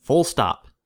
Ääntäminen
IPA : /fʊl stɒp/